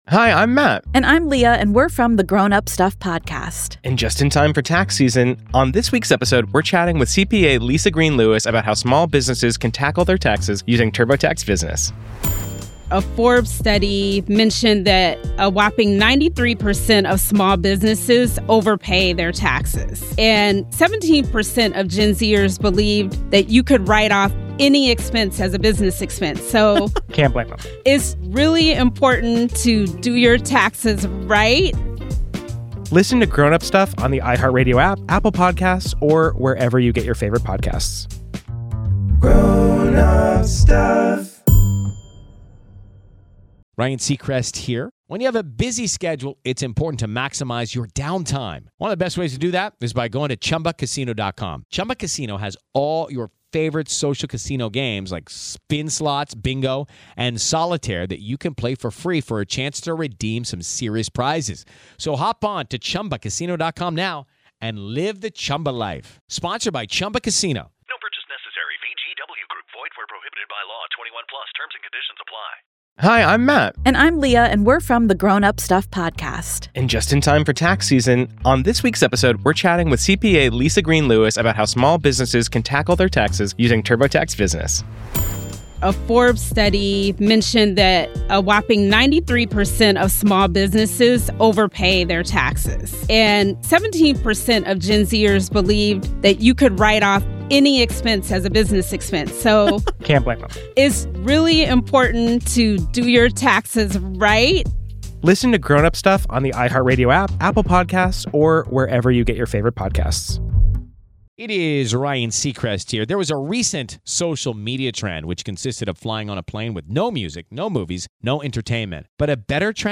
The conversation also compares this case to the Alex Murdaugh case, emphasizing that without a signed verdict, the proceedings must continue from a clean slate.